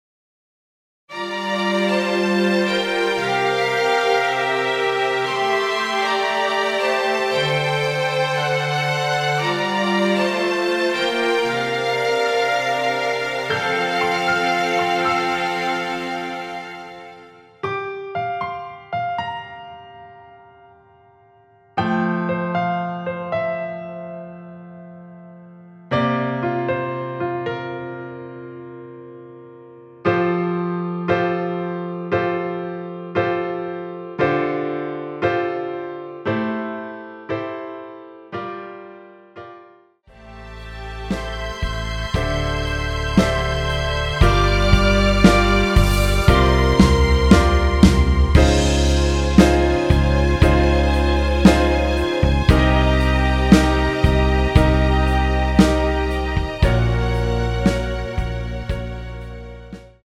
원키에서(+5)올린 MR입니다.
앞부분30초, 뒷부분30초씩 편집해서 올려 드리고 있습니다.
중간에 음이 끈어지고 다시 나오는 이유는